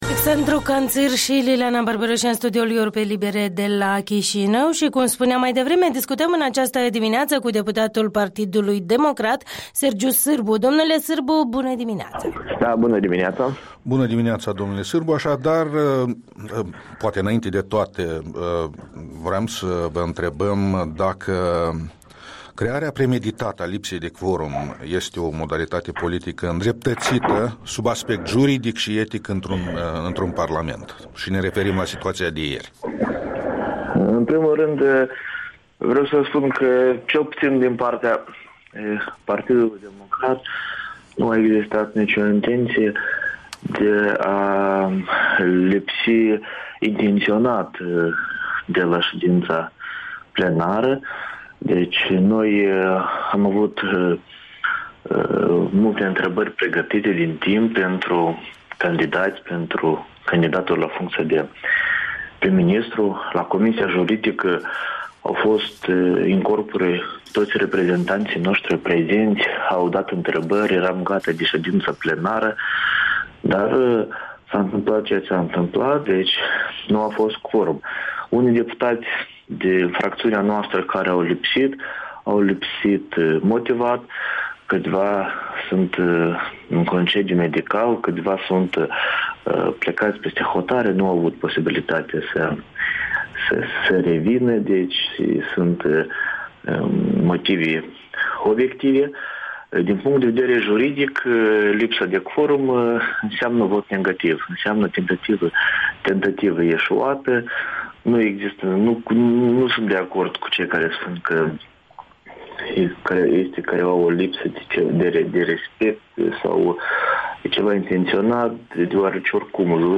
Interviul dimineții cu deputatul PDM.